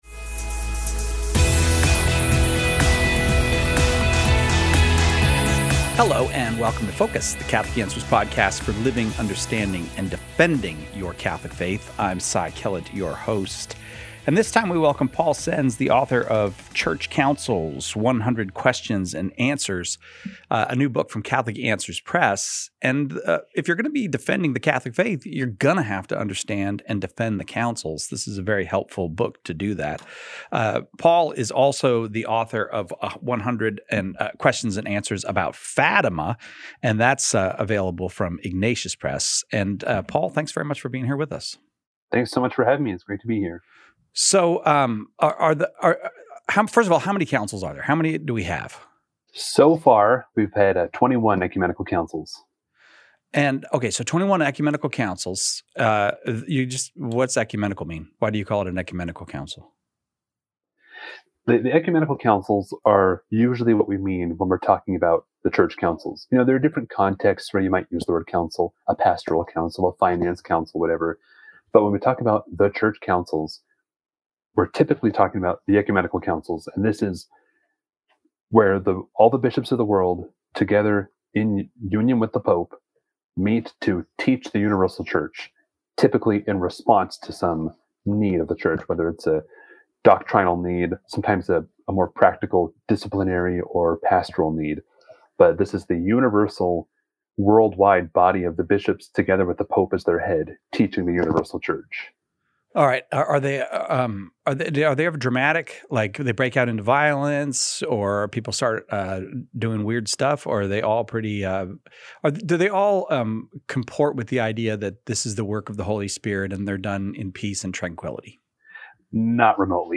Hello and welcome to Focus, the Catholic Answers Podcast for living, understanding and defending your Catholic faith.